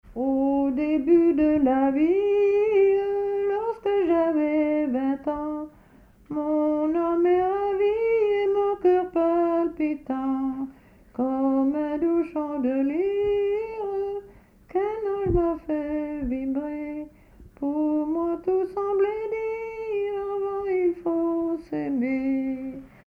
Localisation Bellevaux
Pièce musicale inédite